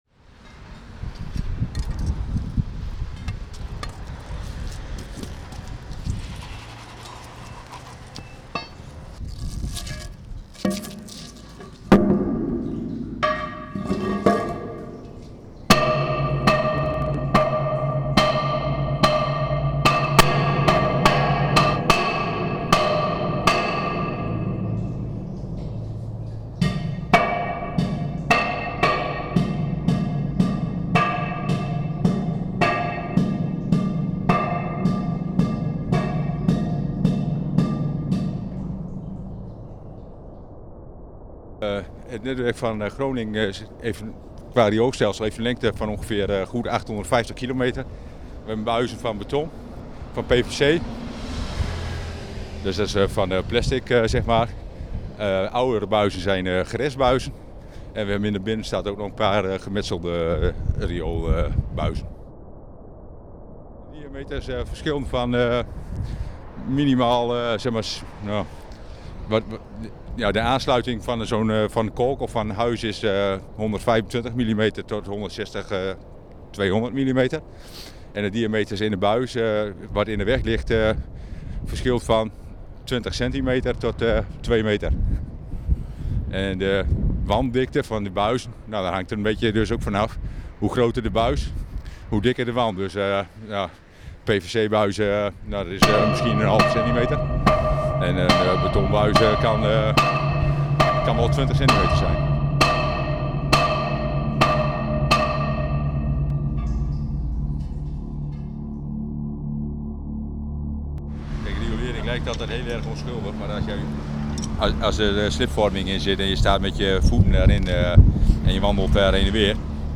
Audio portrait about sewers maintenance for Edge 2.0 radio.